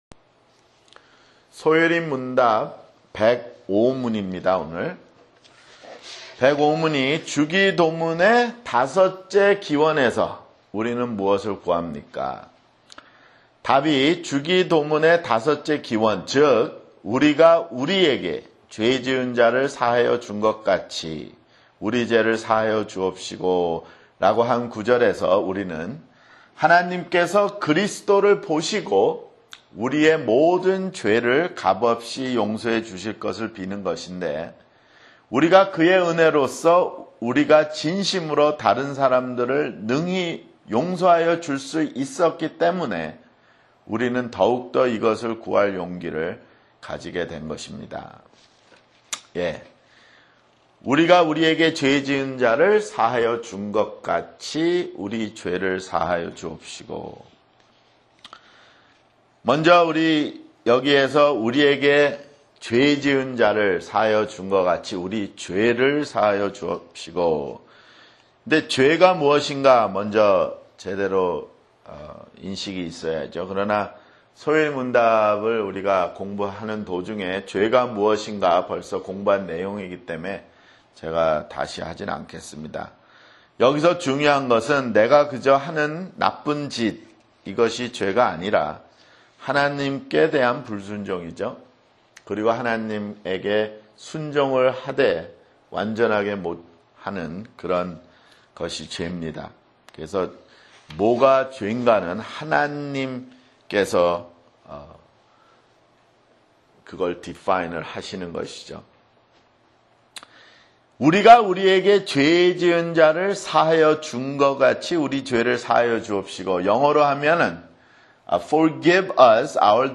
[성경공부] 소요리문답 (80)